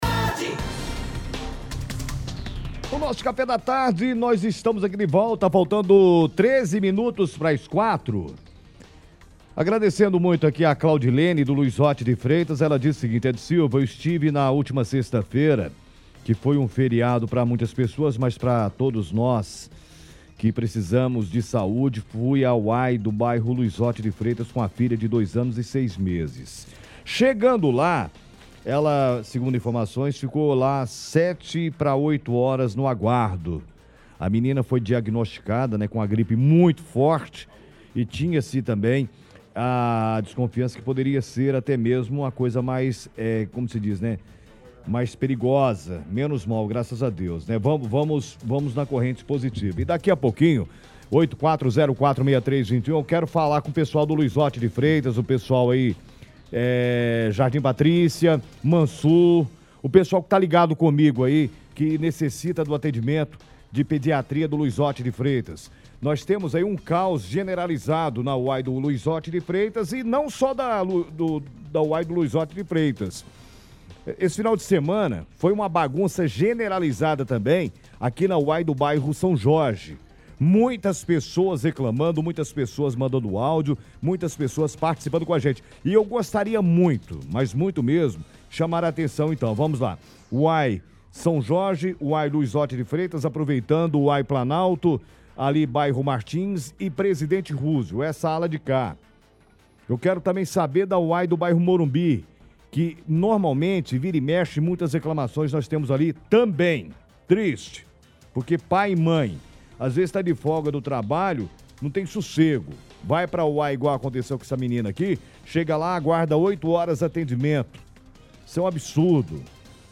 do Conselho Municipal de Saúde *(veicula áudio da briga) *.